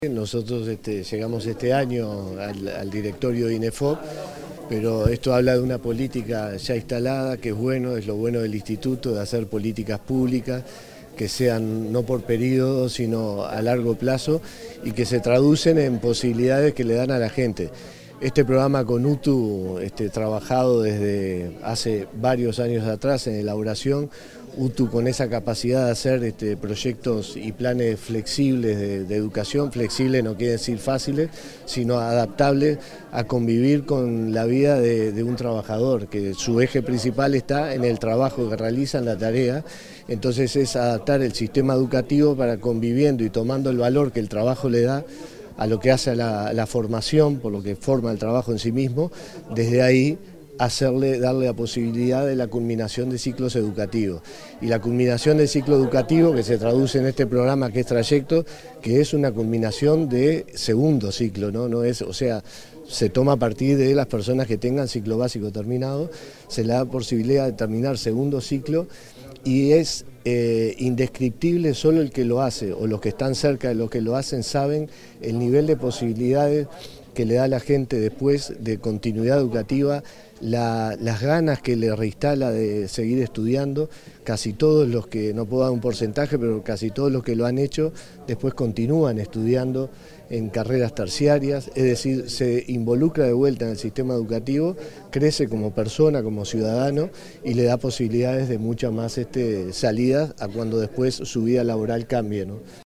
Declaraciones del director general de Inefop, Miguel Venturiello